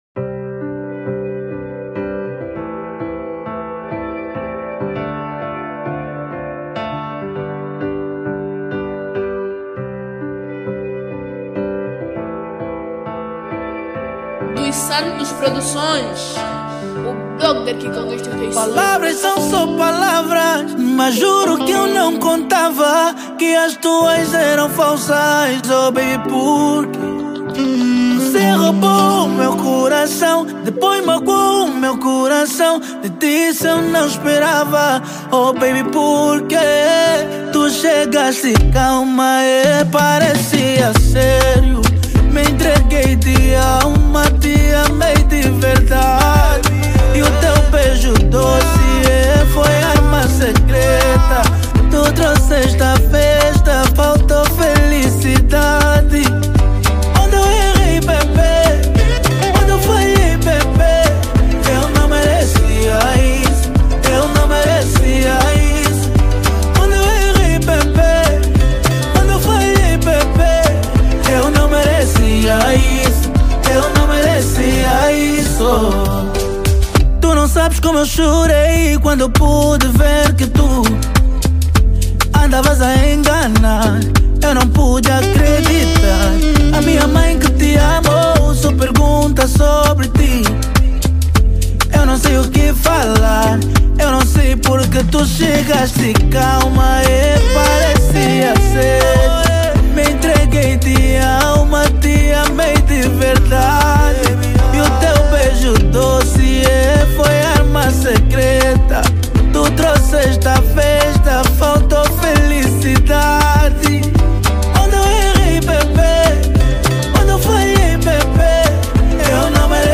Categoria: Afro Pop